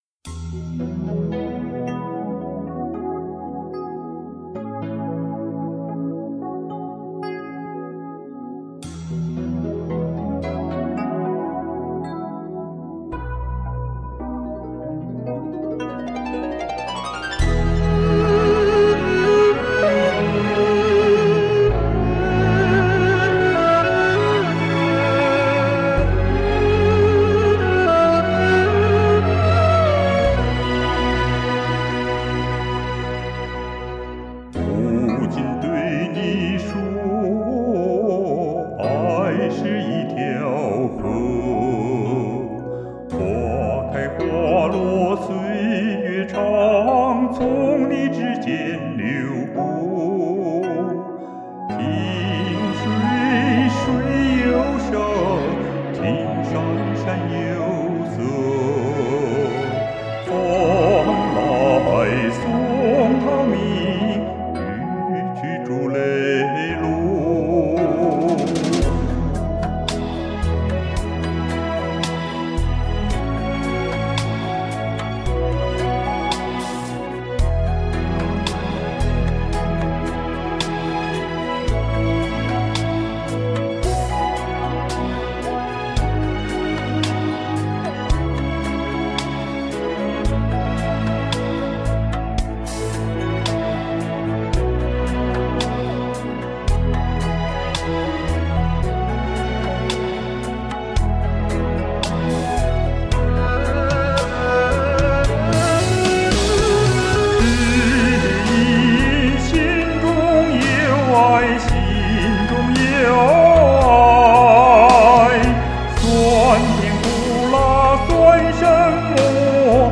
是一首男女声对唱歌曲。我唱了男声部分。